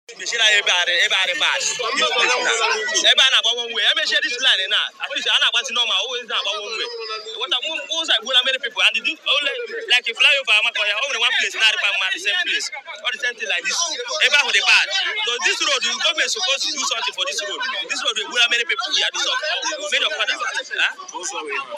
A commercial motorcyclist in the area